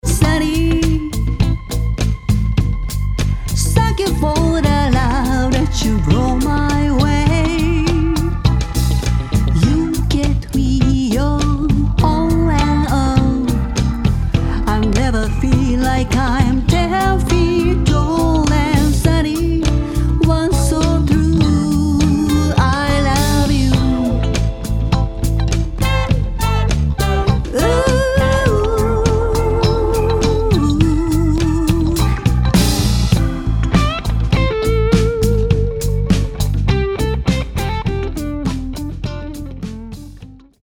70年代ソウルミュージックの名曲をカヴァーしたリスペクトアルバム